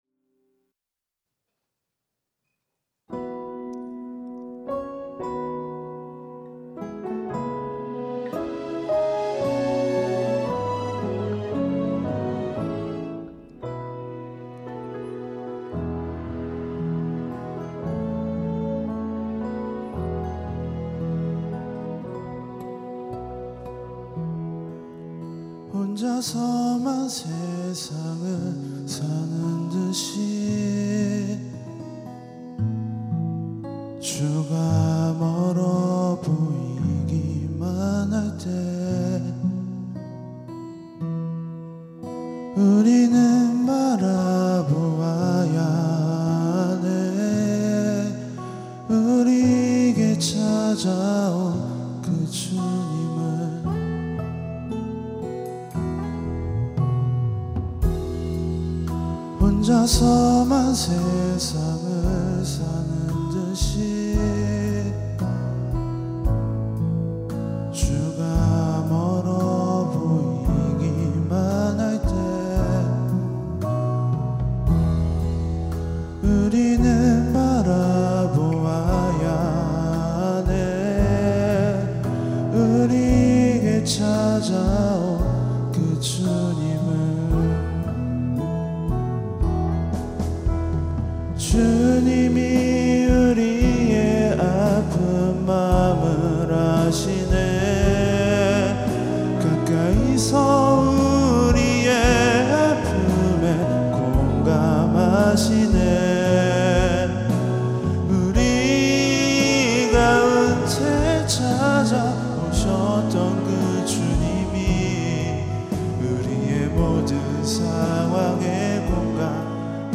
특송과 특주 - 공감하시네